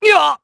Clause_ice-Vox_Damage_kr_01.wav